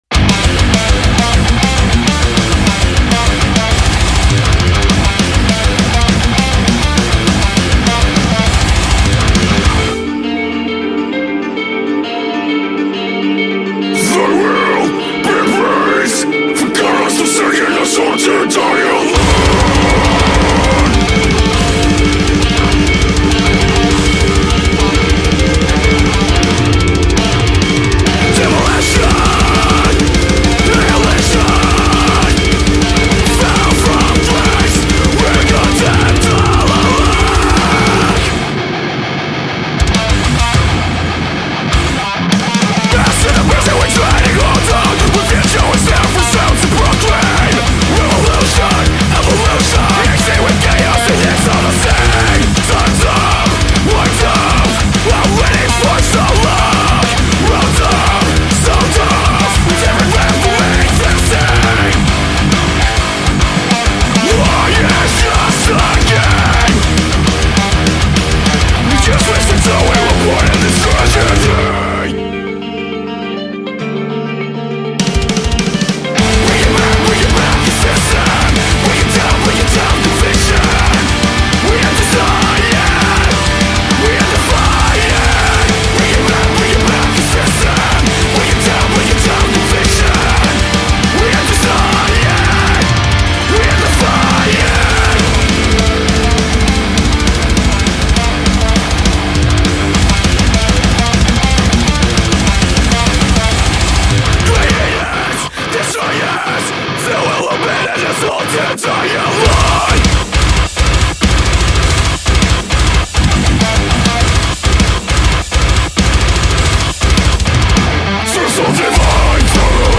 Anyways, here's some more metalcore.